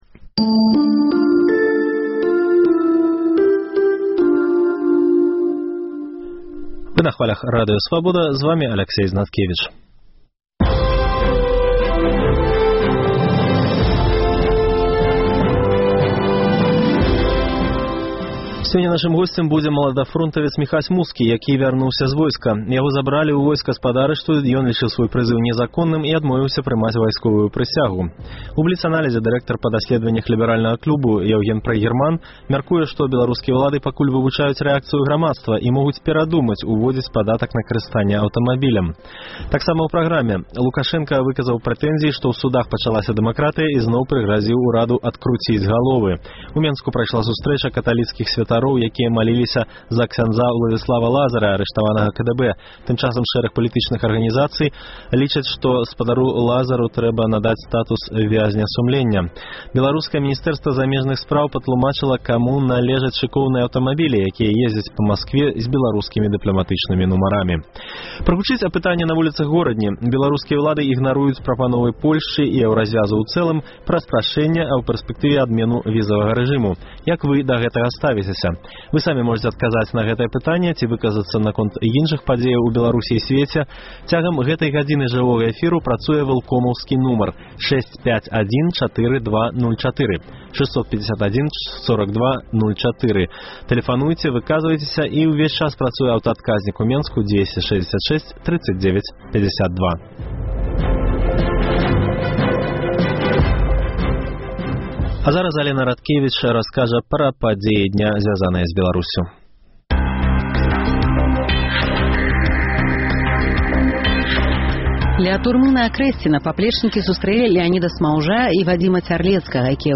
Прагучыць апытаньне